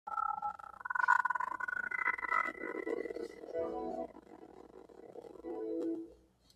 Windows 7 Startup Sound Glitched